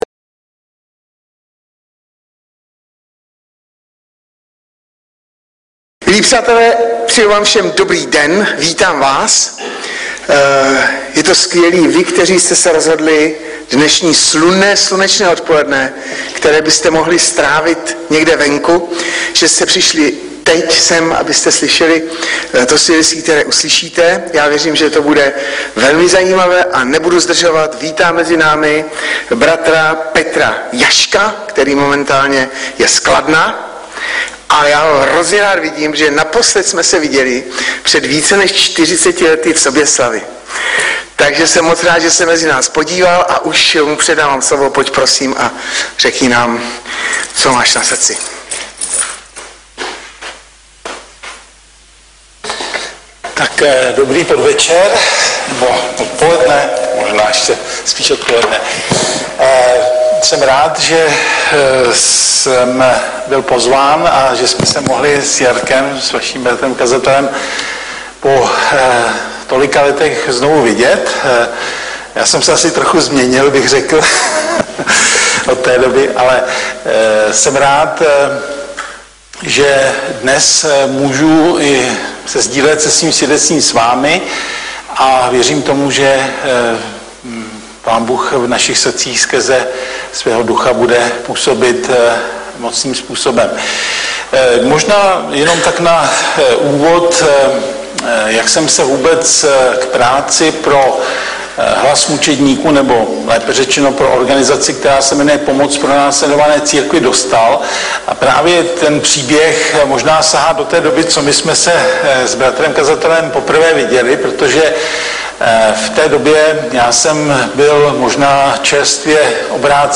Audiozáznam přednášky si můžete také uložit do PC na tomto odkazu.